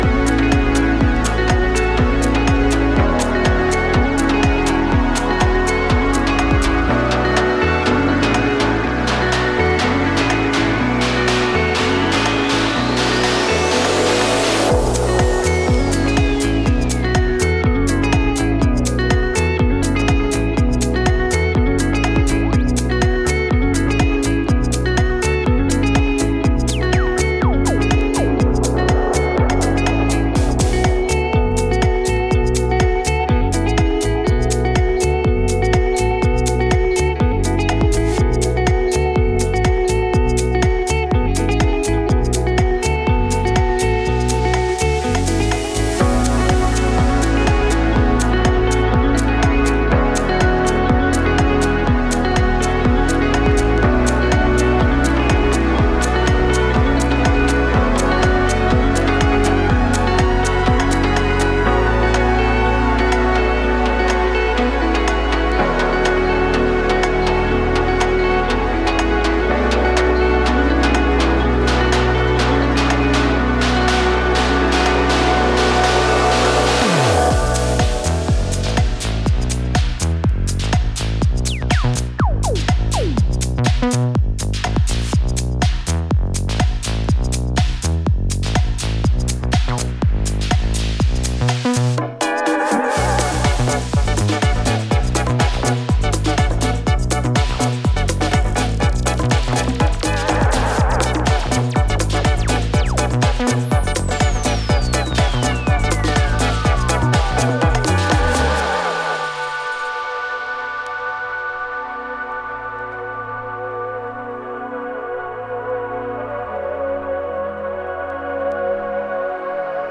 空間に溶け込むギターを効かせながら清涼感と浮遊感を紡ぎ出していく